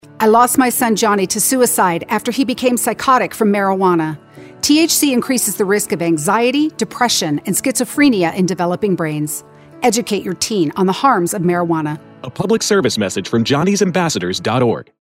Television and Radio PSA Ads Free For You to Use!
RADIO